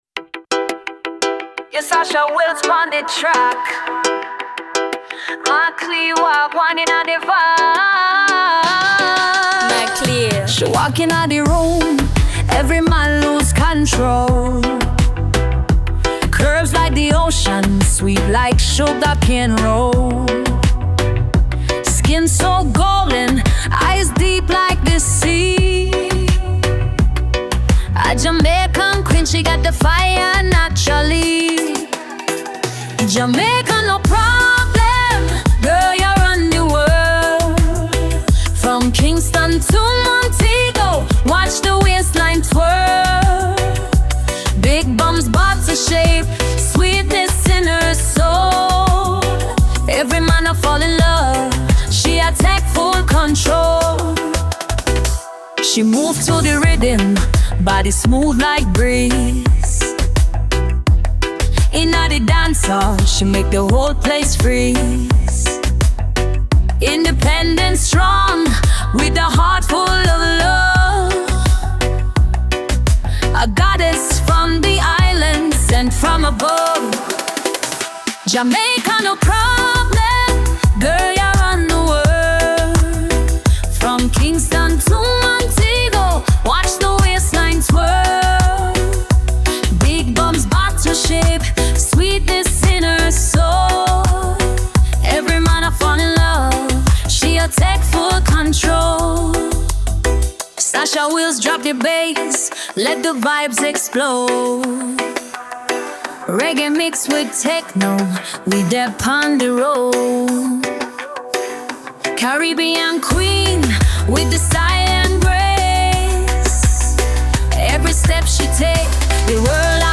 Genre: Reggae, Techno-EDM, Dancehall